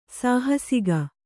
♪ sāhasiga